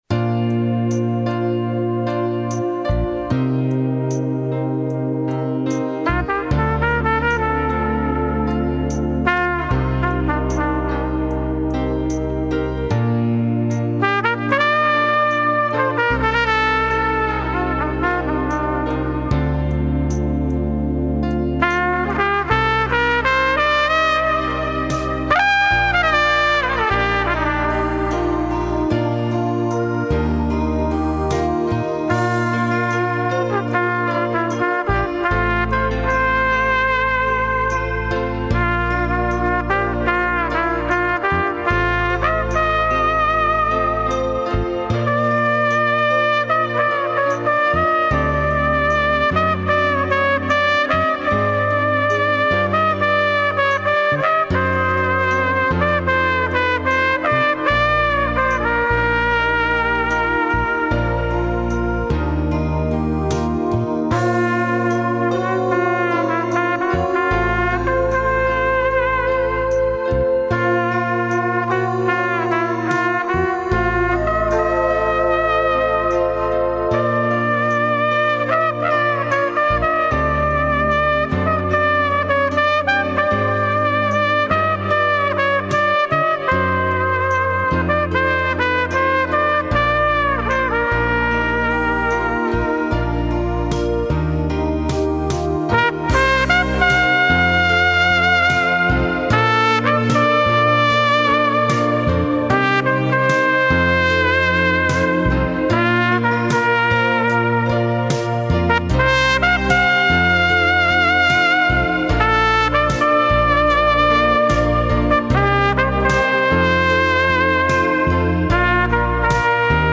ちなみに今日の楽器は引退させようかと思っているYTR634前期型です。
前奏は、「BIB」のイントロコード作成機能を使うとメロディにすんなりとつながる前奏コードを作ってくれますので便利です。